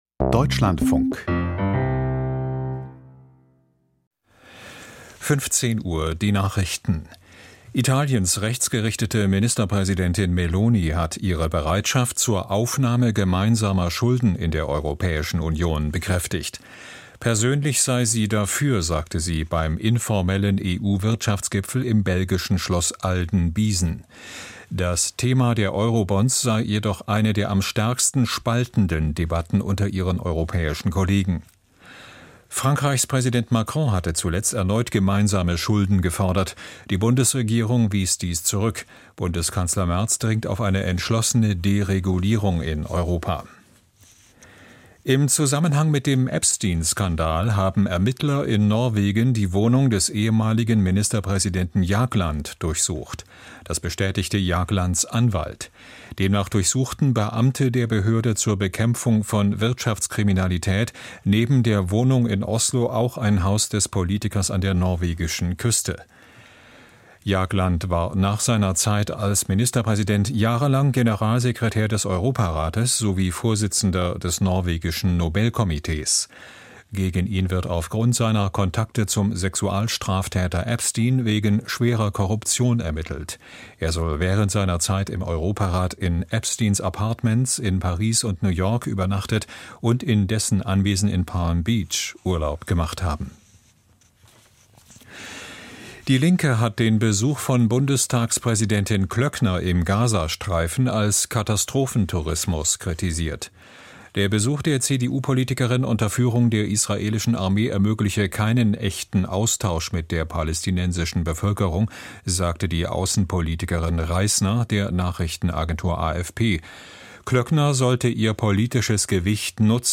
Aktuelle Berichte und Hintergründe